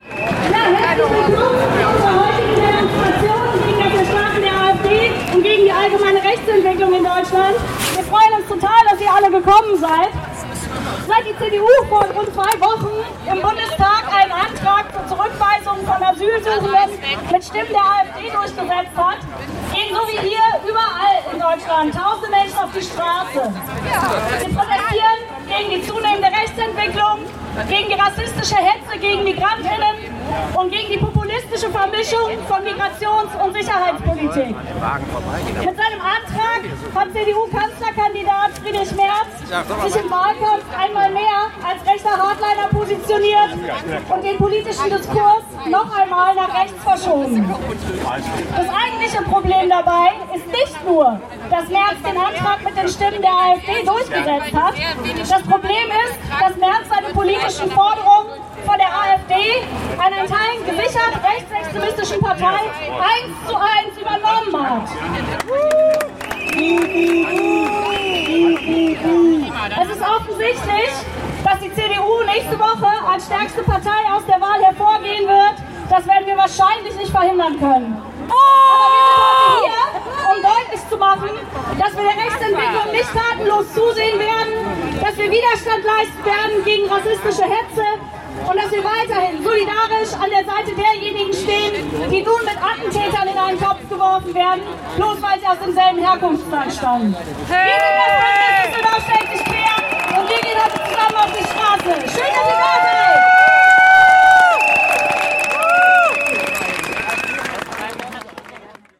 Düsseldorf stellt sich quer: Demonstration „Gegen die AfD und die Rechtsentwicklung der Gesellschaft“ (Audio 1/16)